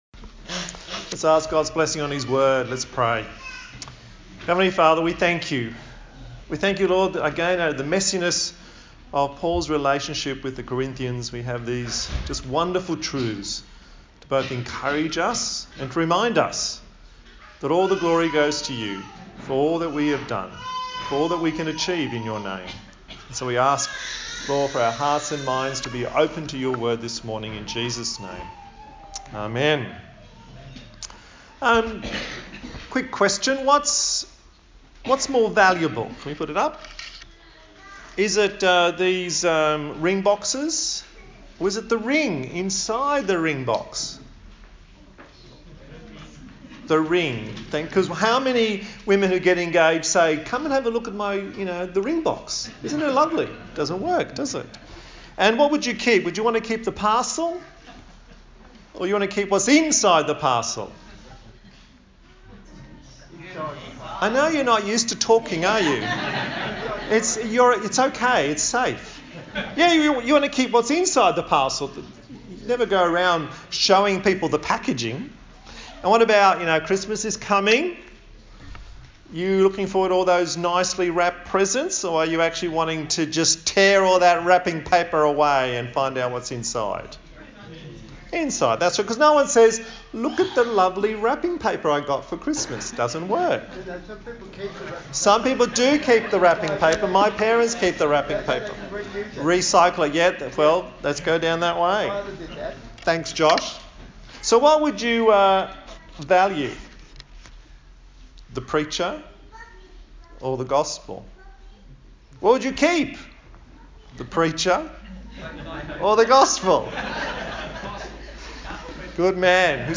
Service Type: Sunday Morning A sermon in the series on the book of 2 Corinthians